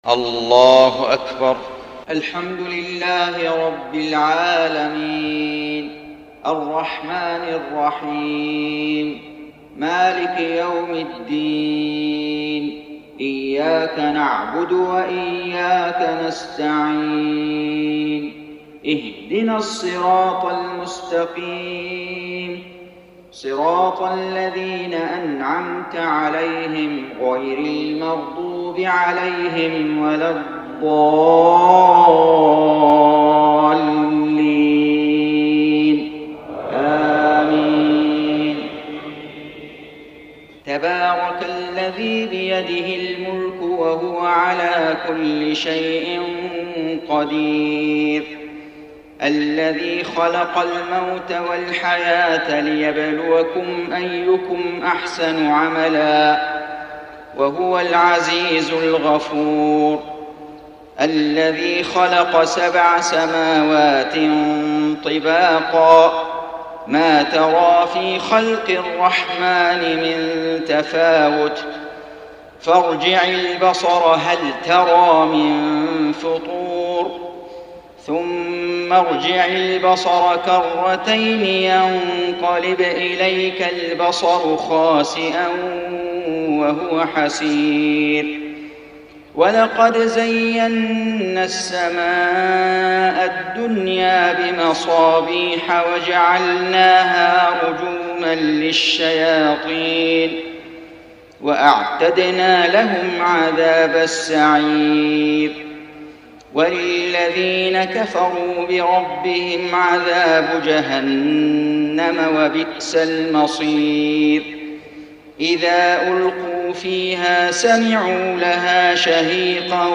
صلاة الفجر 18 رجب 1433هـ سورة الملك > 1433 🕋 > الفروض - تلاوات الحرمين